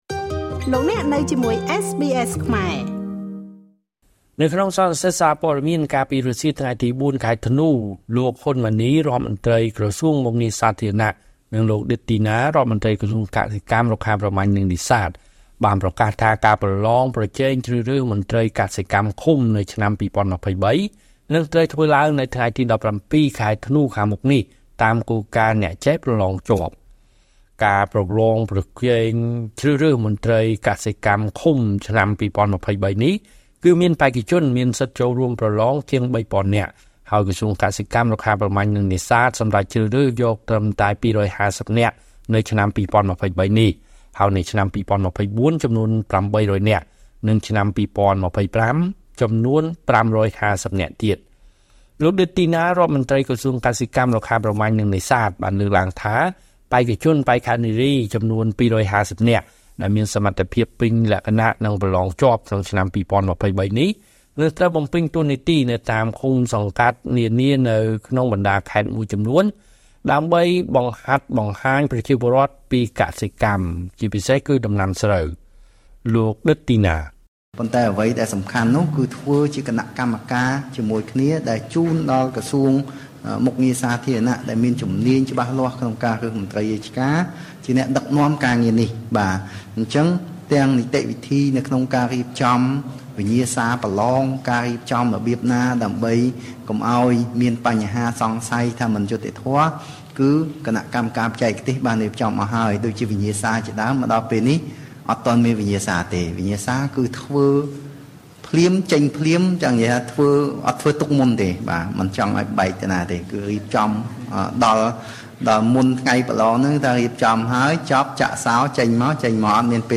នៅក្នុងសន្និសីទសារព័ត៌មាន កាលពីរសៀលថ្ងៃទី៤ ខែធ្នូ ឆ្នាំ២០២៣ លោក ហ៊ុន ម៉ានី រដ្ឋមន្ដ្រីក្រសួងមុខងារសាធារណៈ និងលោក ឌិត ទីណា រដ្ឋមន្ដ្រីក្រសួងកសិកម្ម រុក្ខាប្រមាញ់ និងនេសាទ បានប្រកាសថា ការប្រឡងប្រជែងជ្រើសរើសមន្ដ្រីកសិកម្មឃុំ ឆ្នាំ២០២៣ នឹងត្រូវធ្វើឡើង នៅថ្ងៃទី១៧ ខែធ្នូ ខាងមុខនេះ តាមគោលការណ៍ «អ្នកចេះ ប្រឡងជាប់»។